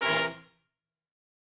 GS_HornStab-C7b2sus4.wav